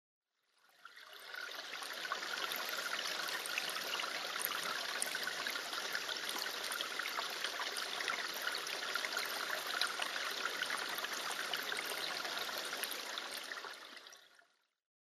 水音　Waters
日光市稲荷川中流　alt=850m  HiFi --------------
Rec.: SONY MZ-NH1
Mic.: Sound Professionals SP-TFB-2  Binaural Souce